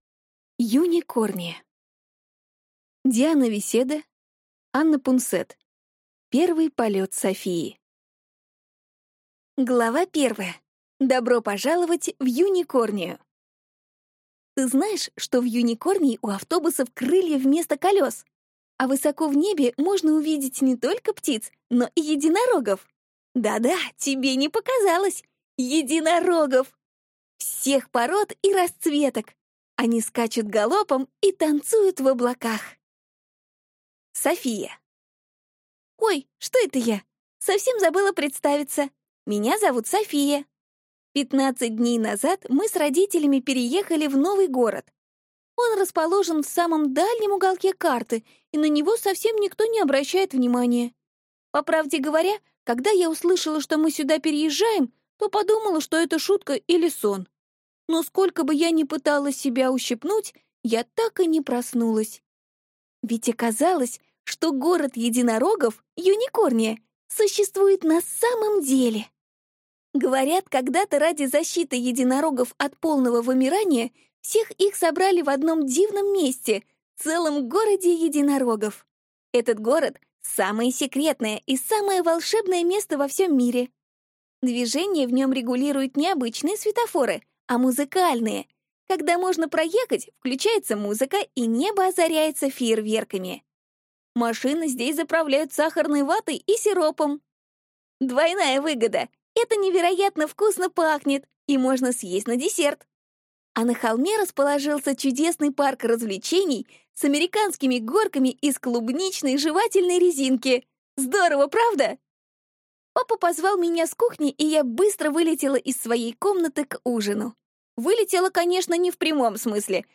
Аудиокнига Первый полёт Софии | Библиотека аудиокниг